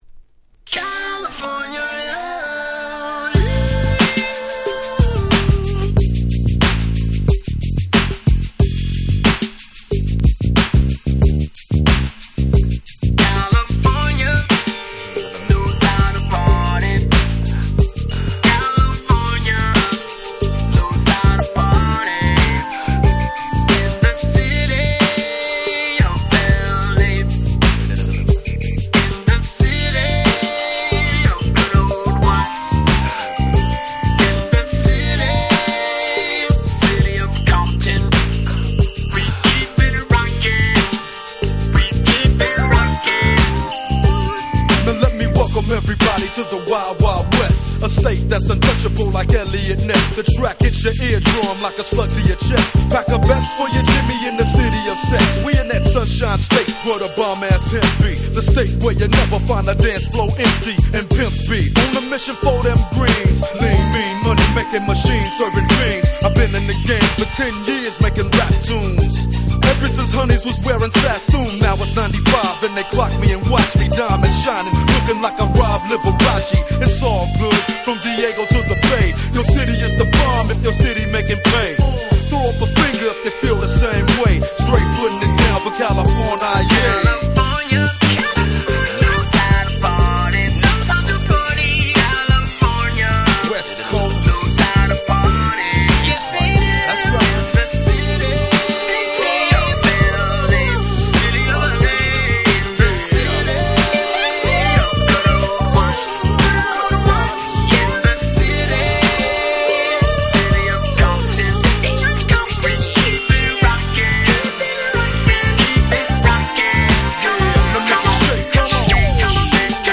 問答無用のWest Coast Hip Hop Classics !!